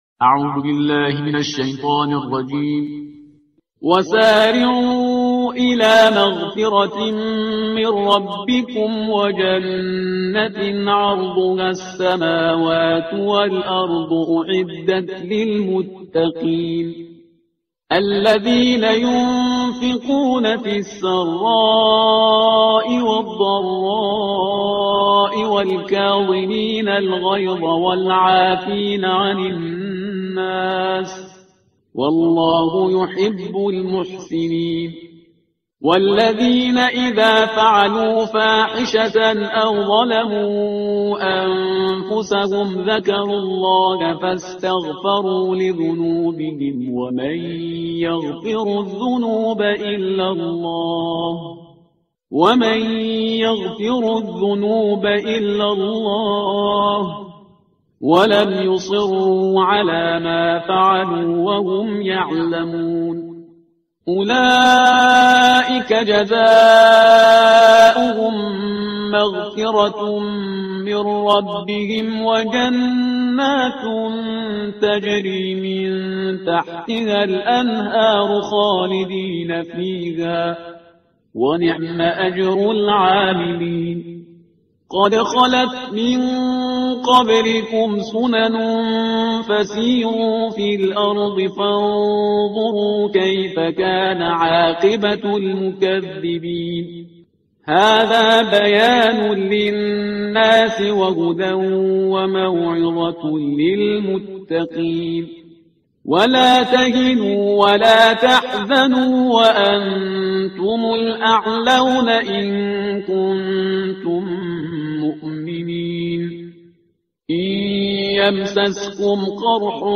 ترتیل صفحه 67 قرآن با صدای شهریار پرهیزگار